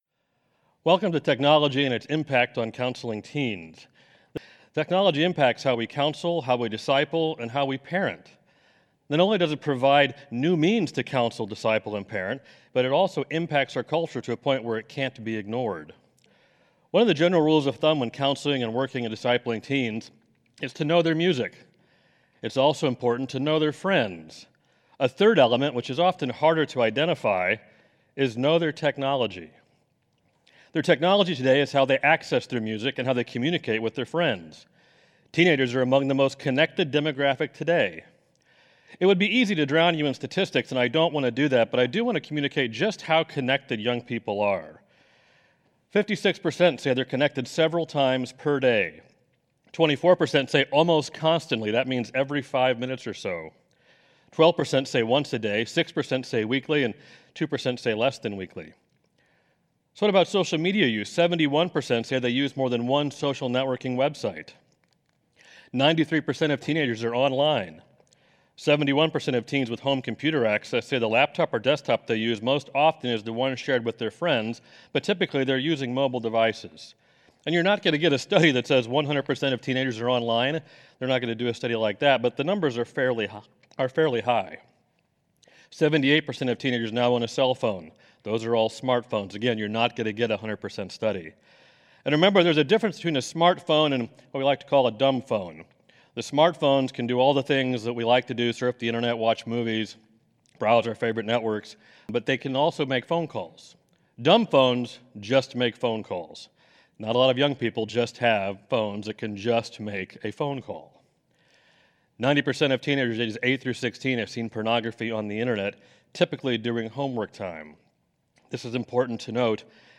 This is a session from the Biblical Counseling Training Conference hosted by Faith Church in Lafayette, Indiana.
You may listen to the first 10 minutes of this session by clicking on the "Preview Excerpt" button above.